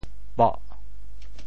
驳（駁） 部首拼音 部首 马 总笔划 7 部外笔划 4 普通话 bó 潮州发音 潮州 boh4 白 中文解释 驳 <形> (会意。